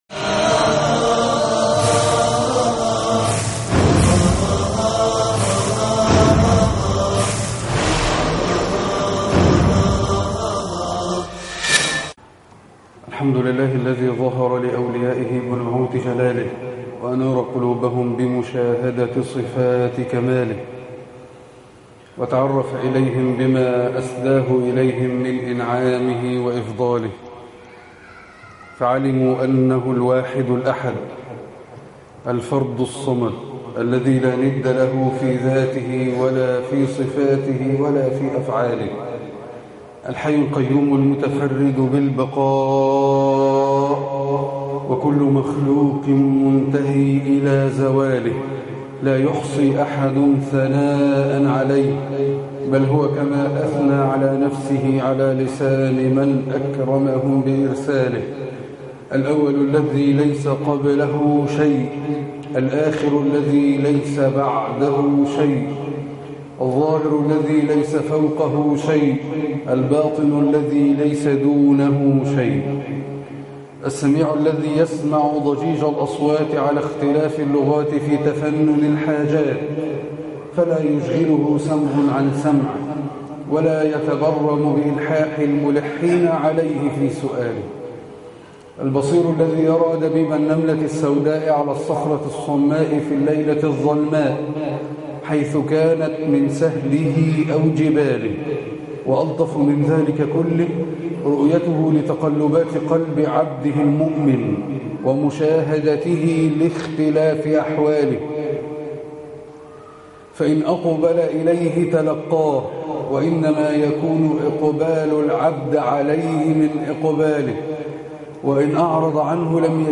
مسجد أهل السنة بالمنصورة